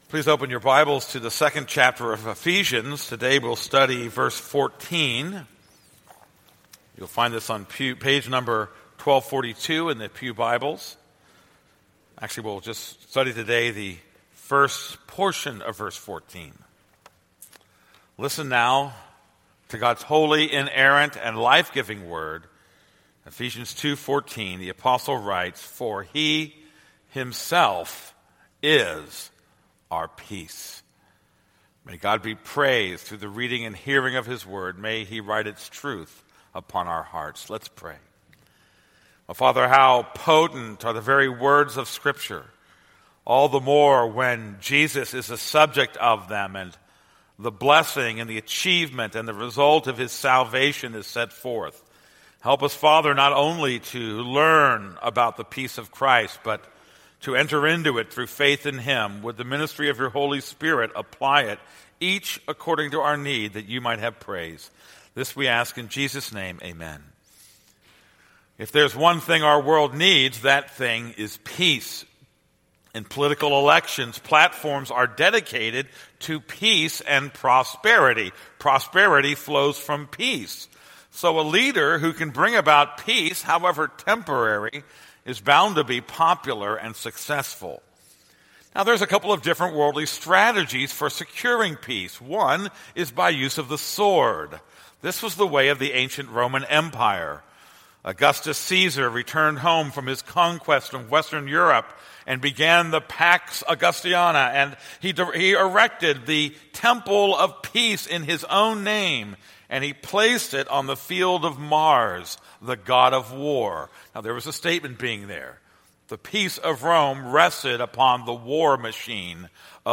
This is a sermon on Ephesians 2:14.